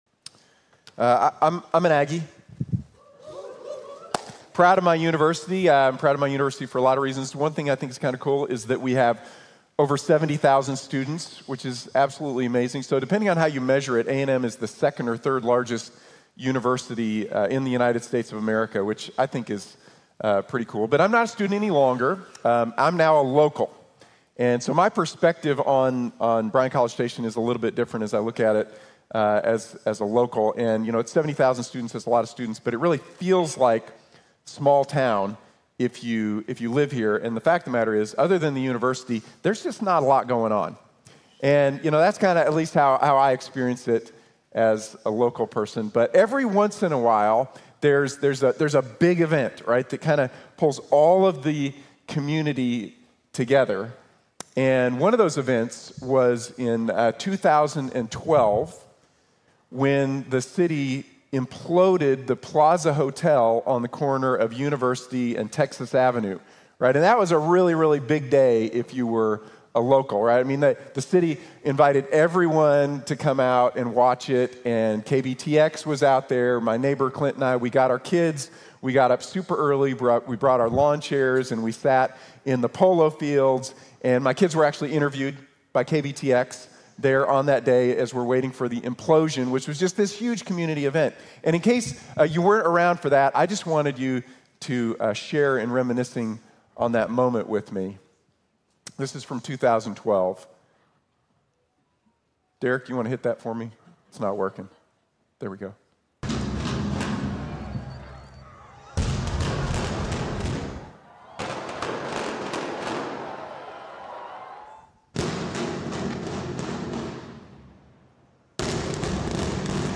Flee from Babylon | Sermon | Grace Bible Church